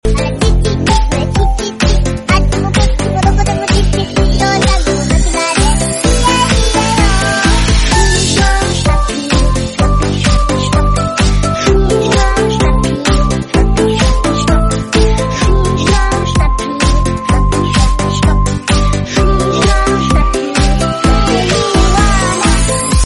Đồ Chơi Thỏ Lắc Lư Sound Effects Free Download